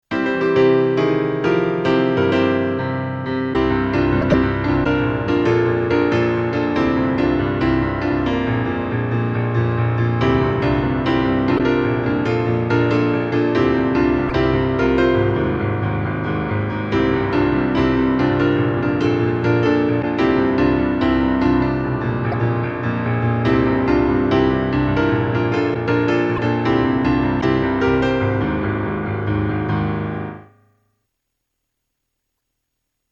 Piano
勝利ファンファーレ2
P.Sまぁ音が悪いのは、レコードのせいだと好意的に解釈してください。